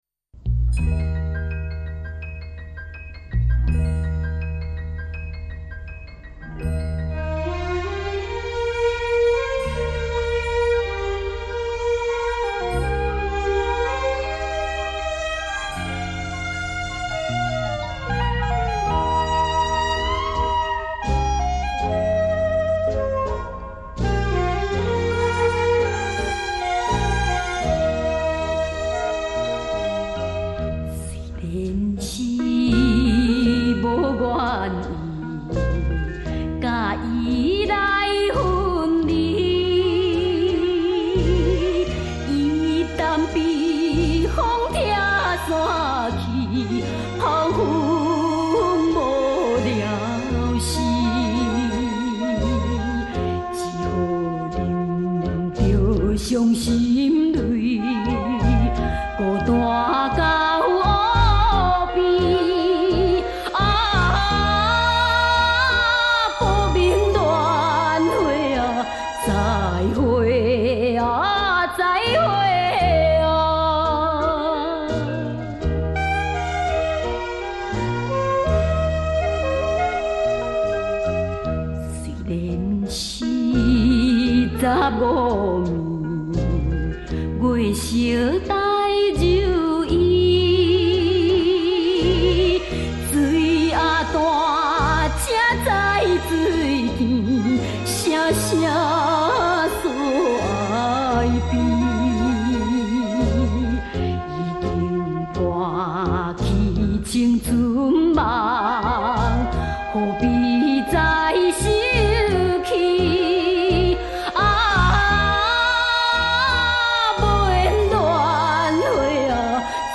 他的音色特別、唱腔獨特，是別人無法模仿的。